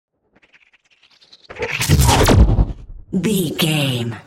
Sci fi hit technology electricity
Sound Effects
heavy
intense
dark
aggressive